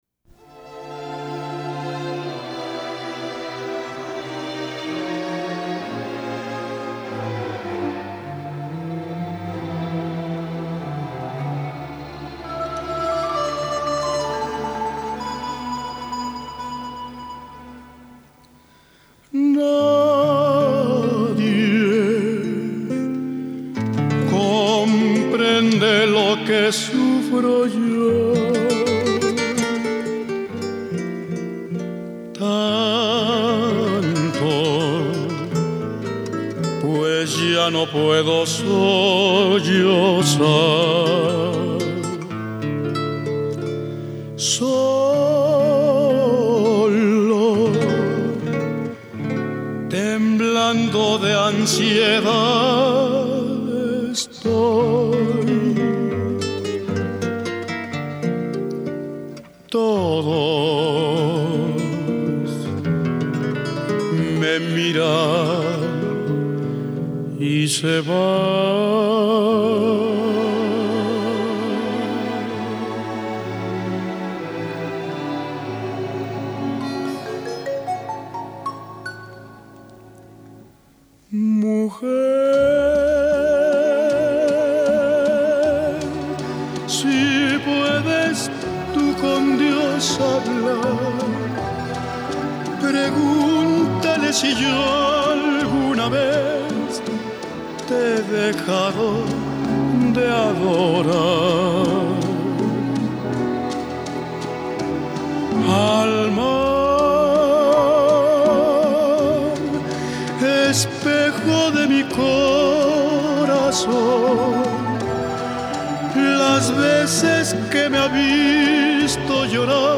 bolero
personificó e interpretó al amante incomprendido.
En Nueva York